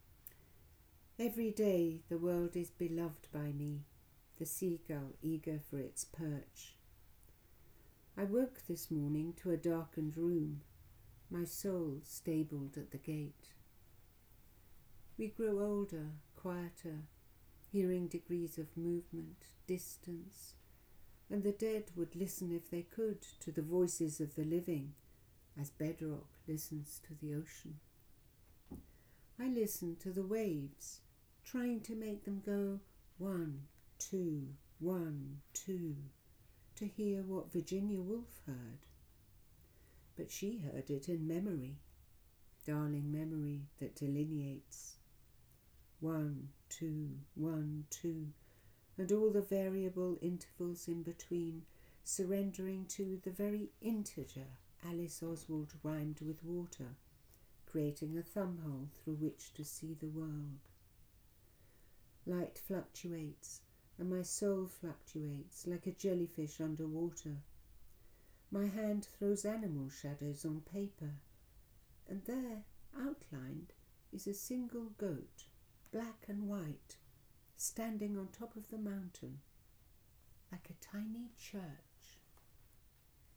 Select this track to hear Mimi Khalvati reading “The Waves”: